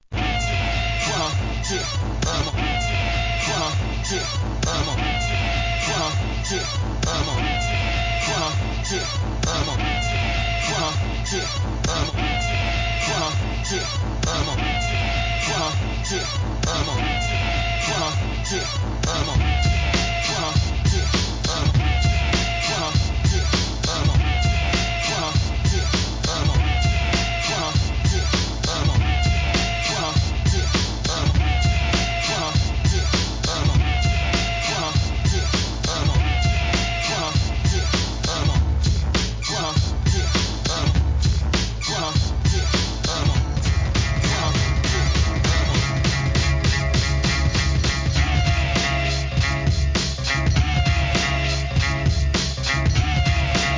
HIP HOP/R&B
太いBEATに様々な声ネタを乗せるB/Wがオススメ!!